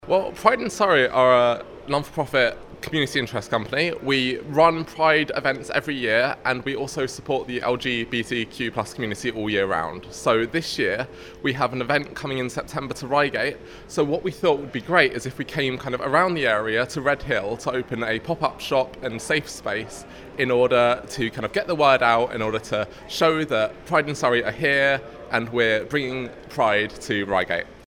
Pride-in-Surrey-news-clip.mp3